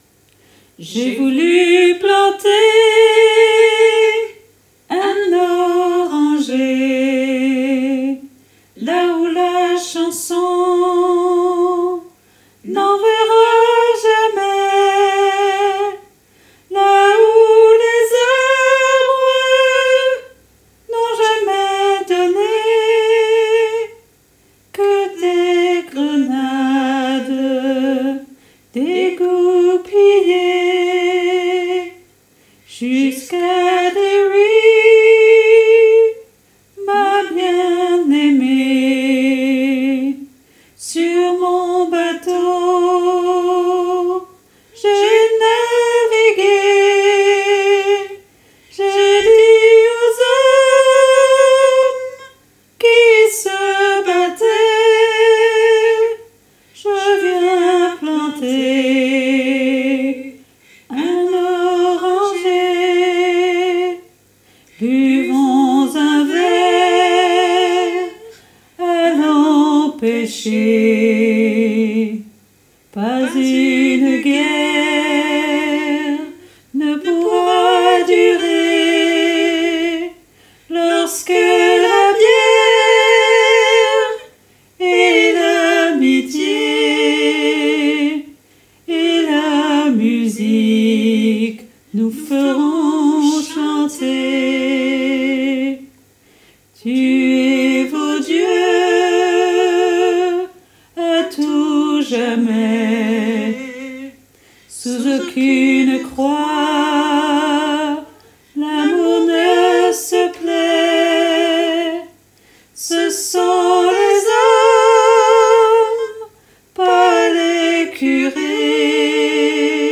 MP3 versions chantées
Alto Et Autres Voix En Arriere Plan